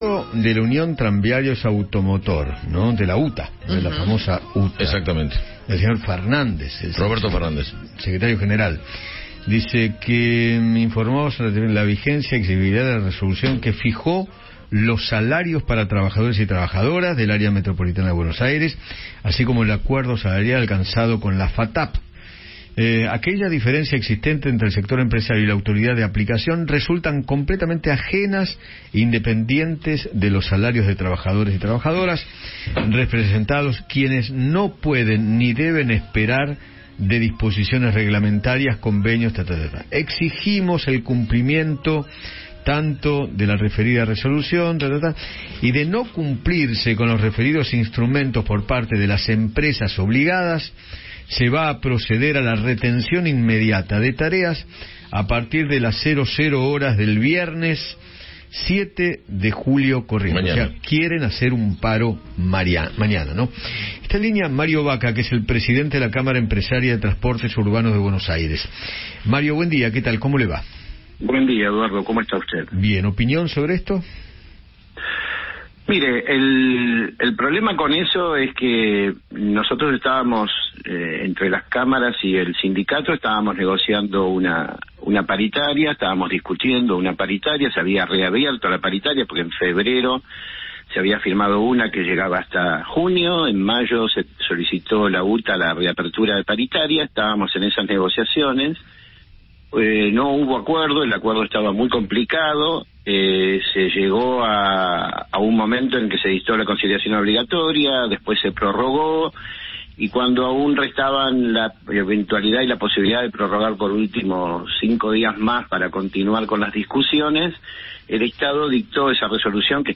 dialogó con Eduardo Feinmann sobre el paro de colectivos que anunció la UTA para mañana.